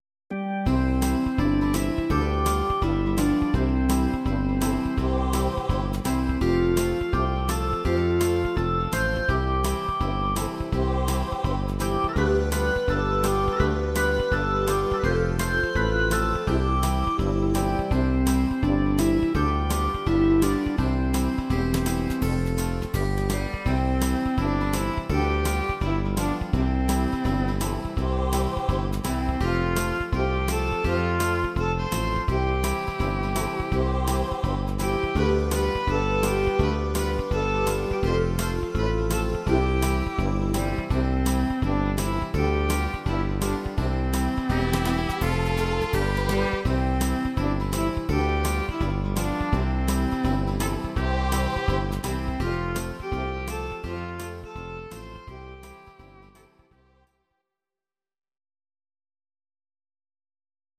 Audio Recordings based on Midi-files
Our Suggestions, Pop, German, 1970s